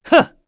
Esker-Vox_Attack2_kr.wav